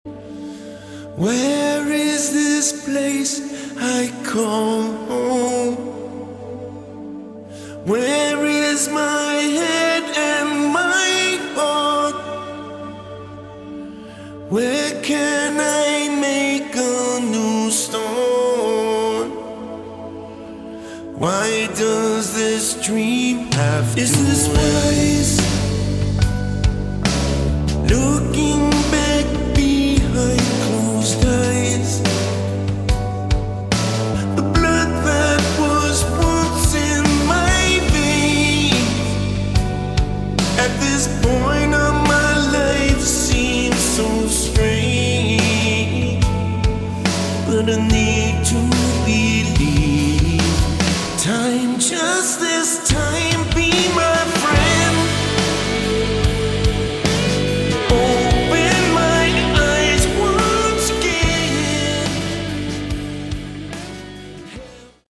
Category: AOR / Melodic Rock
lead vocals
guitars